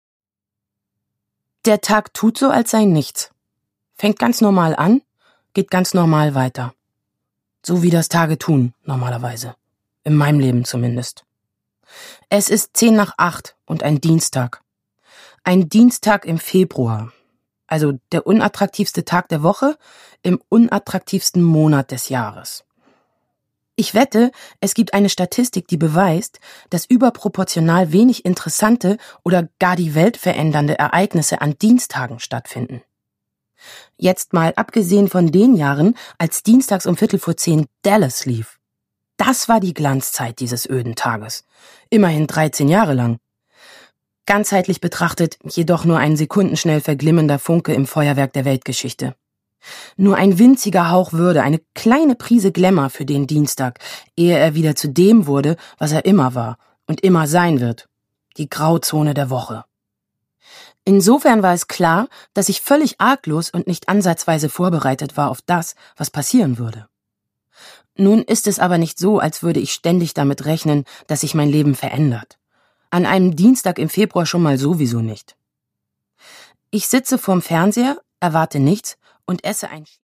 Produkttyp: Hörbuch-Download
Fassung: Autorisierte Lesefassung
Gelesen von: Anneke Kim Sarnau